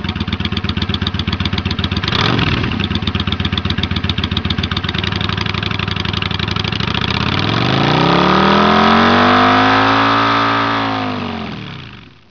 But it sounds great!  Way more loudness and it rumbles!   Here is a recording of the pipes:
This one was digitally recorded in my driveway - no processing of the sound.